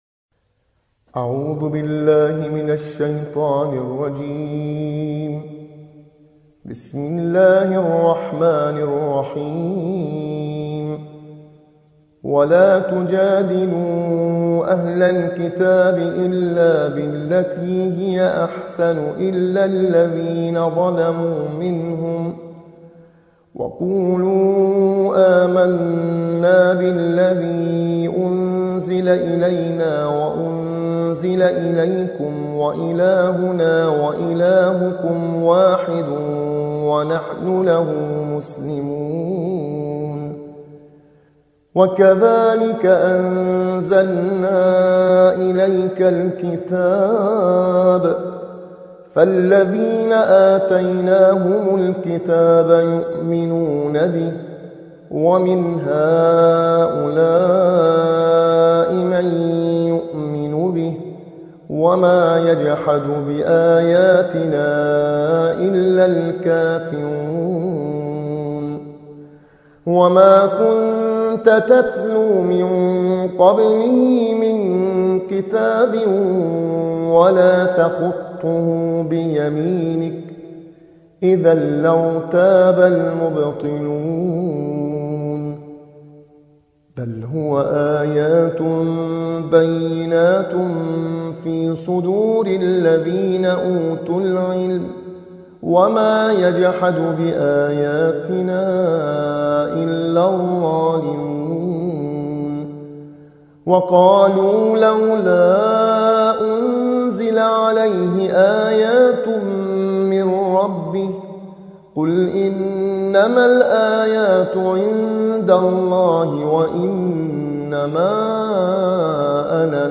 صوت | ترتیل‌خوانی جزء ۲۱ قرآن